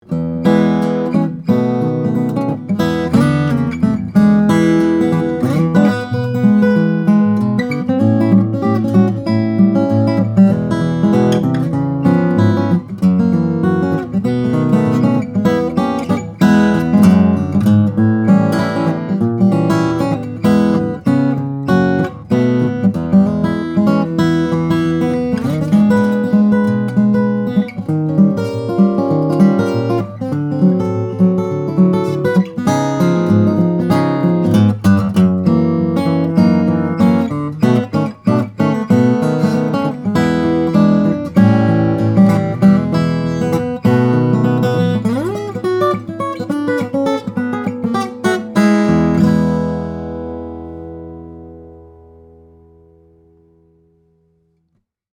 Fresh on the scene, this 2014 Bourgeois OM Custom has lots of bark to bring to the table. The Brazilian Rosewood back and sides and Adirondack Spruce top are voiced with projection in mind, so this guitar can easily fill a room with its warm, bright tone.